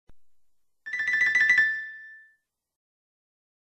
SFX轻松滑稽的搞笑的钢琴素材音效下载
这是一个免费素材，欢迎下载；音效素材为轻松滑稽的搞笑的钢琴音效素材， 格式为 mp3，大小1 MB，源文件无水印干扰，欢迎使用国外素材网。